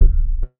BWB WAV 7 PERC (36).wav